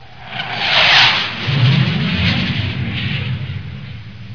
دانلود آهنگ طیاره 43 از افکت صوتی حمل و نقل
دانلود صدای طیاره 43 از ساعد نیوز با لینک مستقیم و کیفیت بالا
جلوه های صوتی